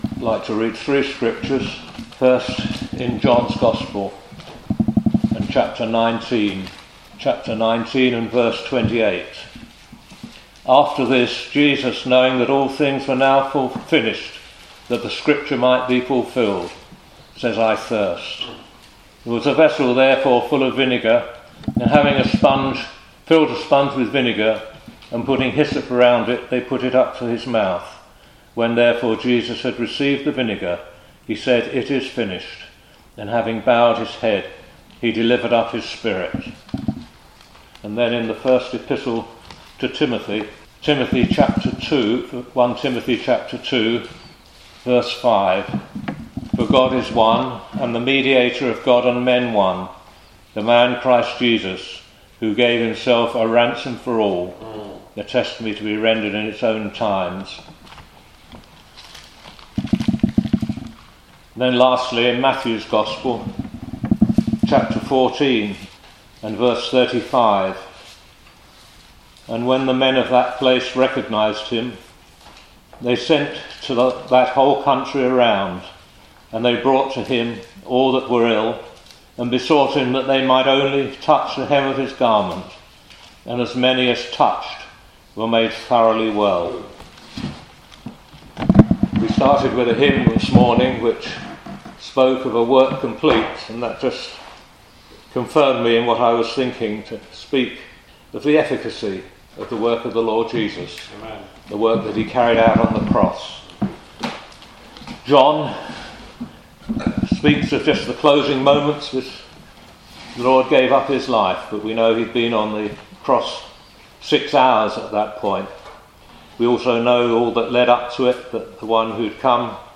The cross was not a partial work — it was a perfect one. This preaching reveals how Jesus’ finished work brings full forgiveness, lasting peace, and eternal salvation to all who believe.